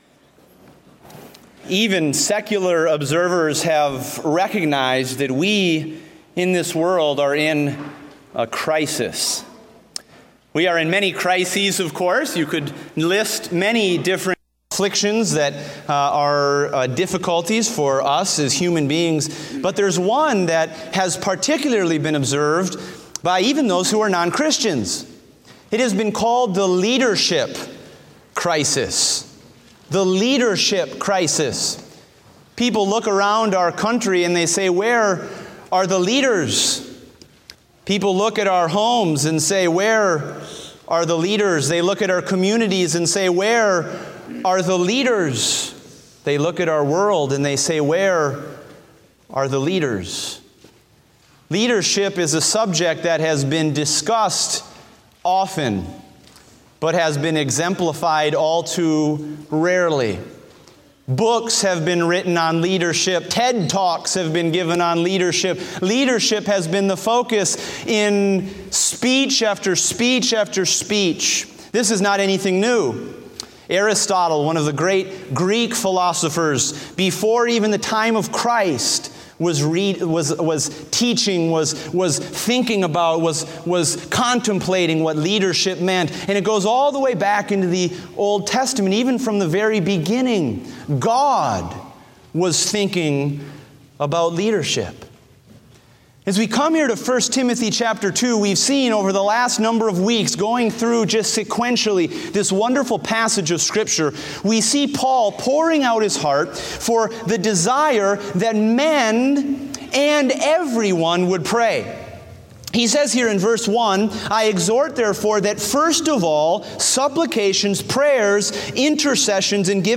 Date: October 2, 2016 (Adult Sunday School)